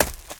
STEPS Leaves, Run 16.wav